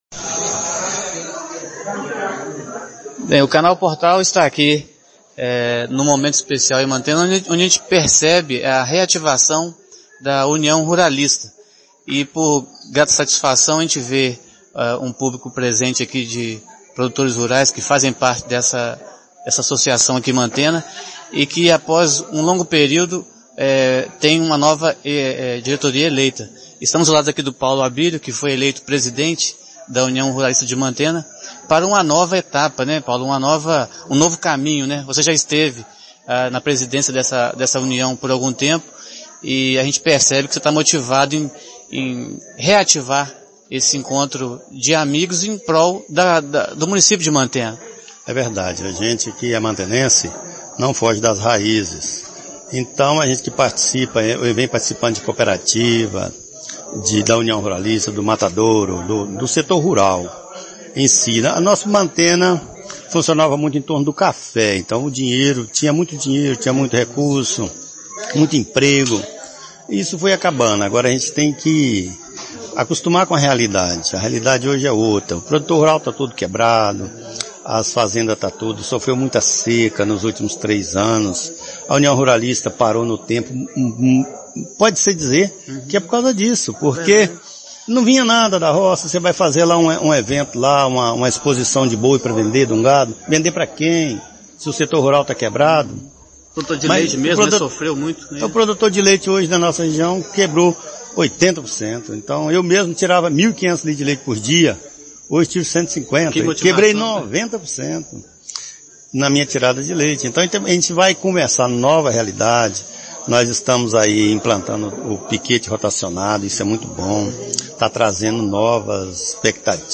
Rádio PORTAL entrevista